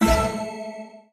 Appear_Small_Total_Win_Sound.mp3